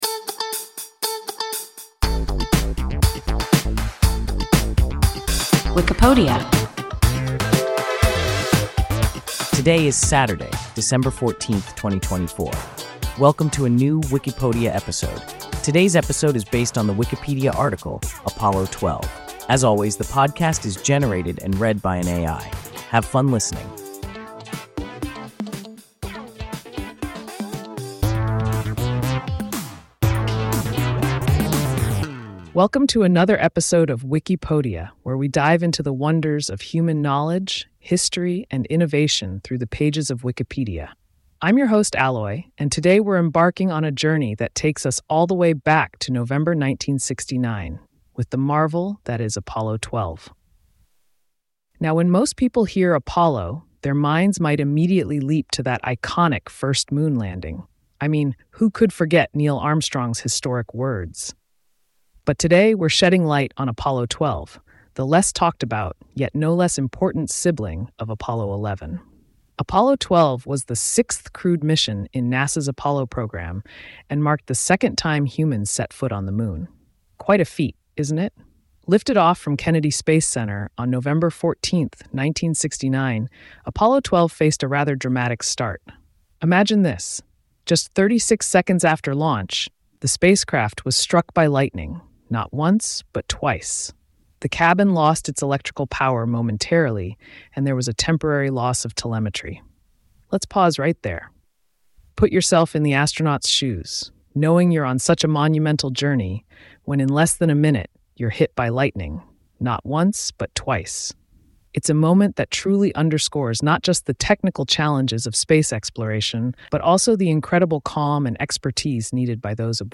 Apollo 12 – WIKIPODIA – ein KI Podcast